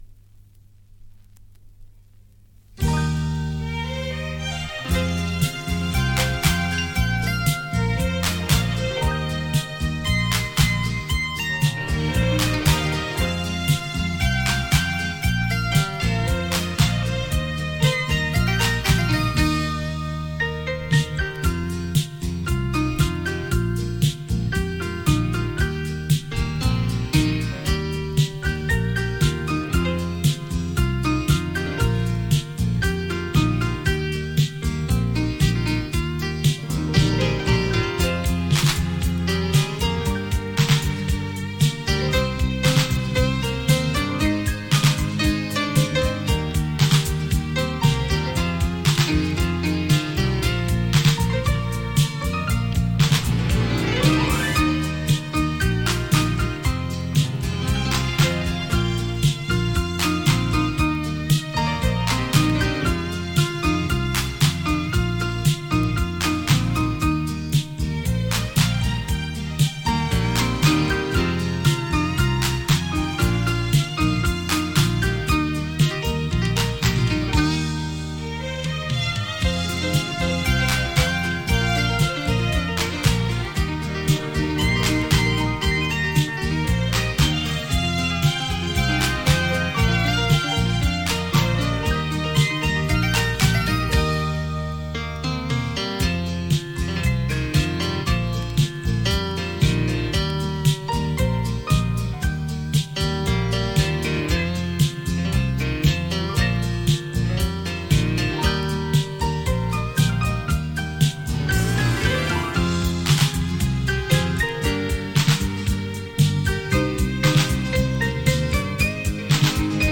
令人回味的音韵 仿佛回到过往的悠悠岁月